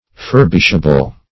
Furbishable \Fur"bish*a*ble\, a. Capable of being furbished.